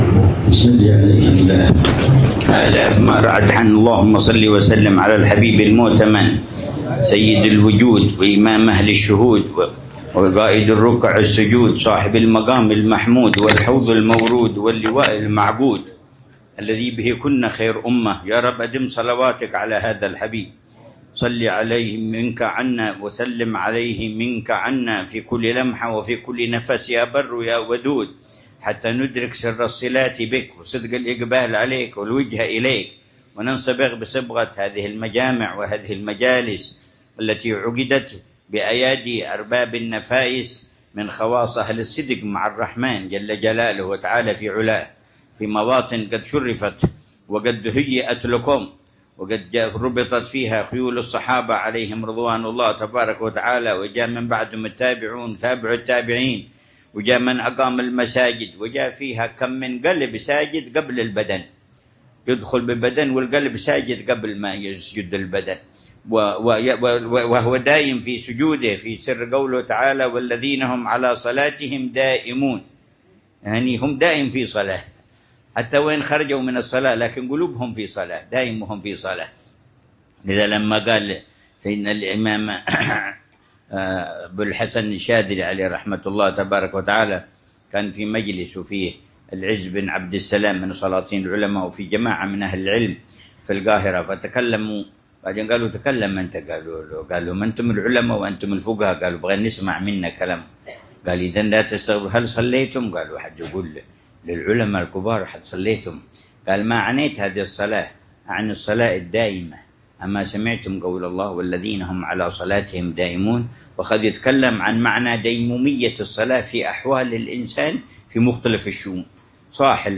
مذاكرة العلامة الحبيب عمر بن محمد بن حفيظ في مسجد الرباط، في حارة الرضيمة بمدينة تريم، ليلة الأربعاء 18 ربيع الأول 1447هـ بعنوان :